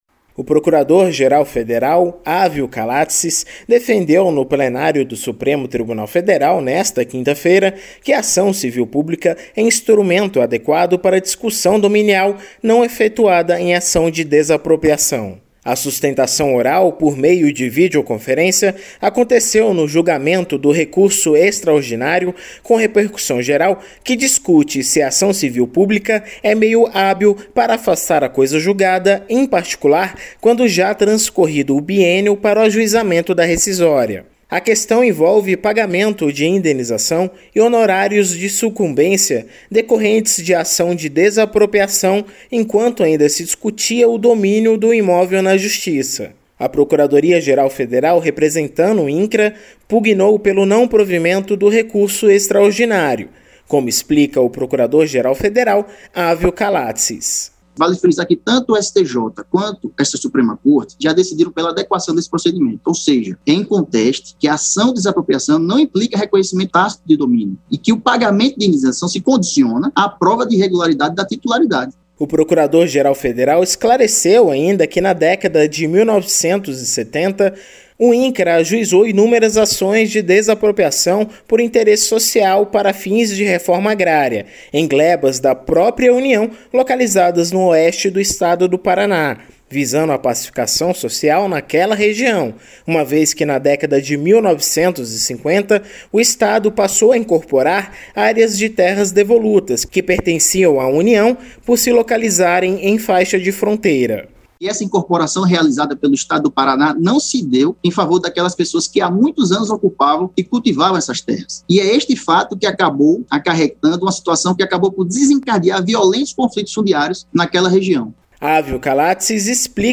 O Procurador-Geral Federal, Ávio Kalatzis, fez sustentação oral durante julgamento